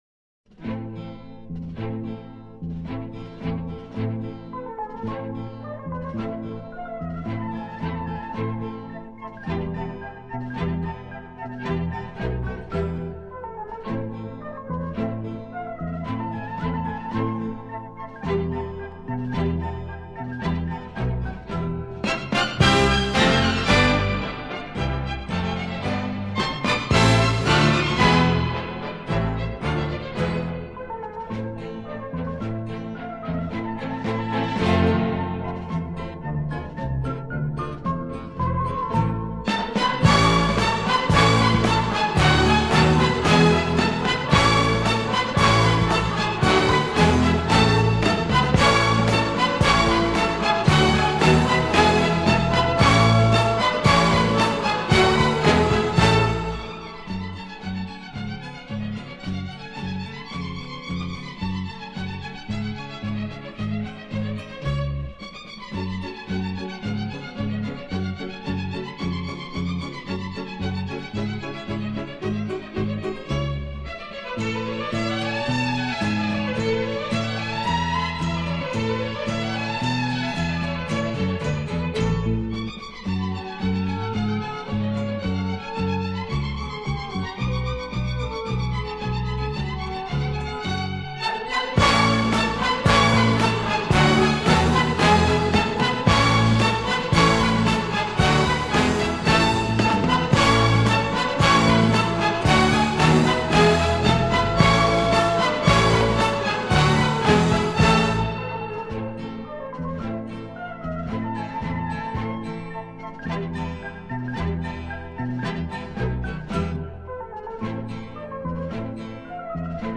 Genre:Jazz,Classical
Style:Romantic,Easy Listening